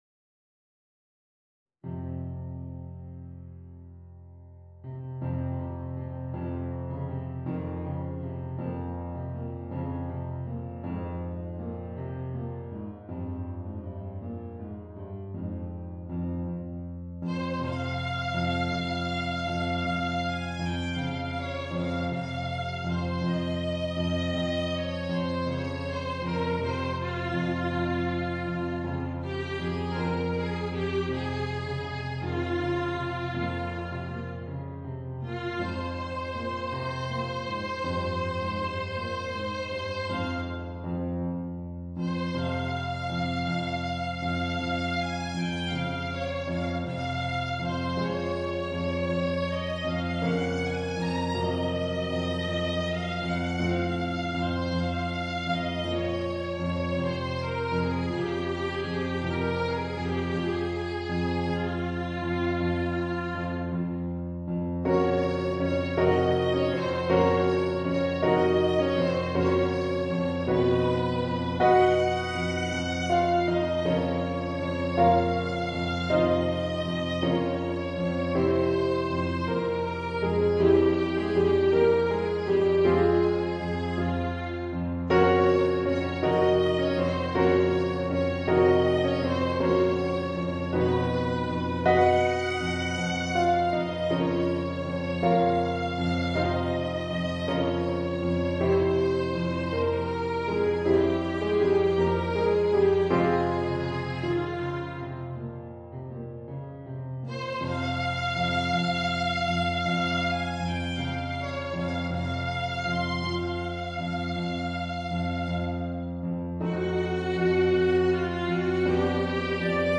Voicing: Violin and Piano